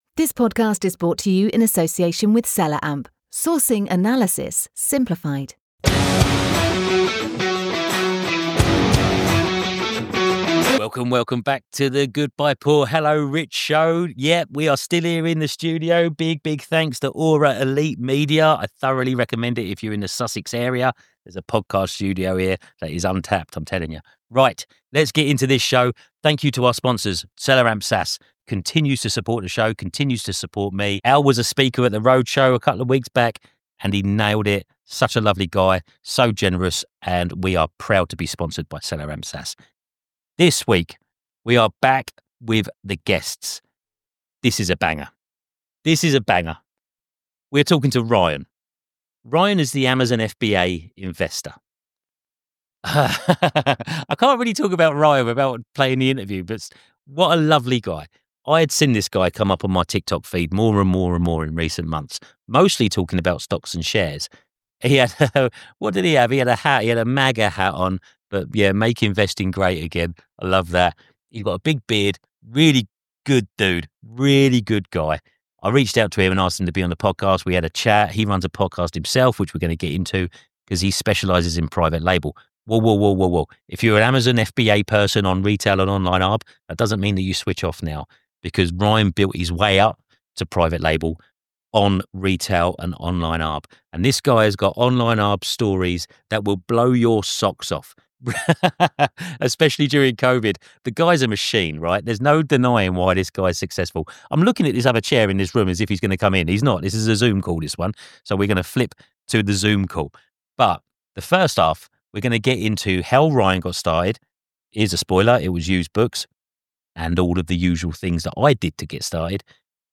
This week, we're back with a guest, and trust me, this is a banger!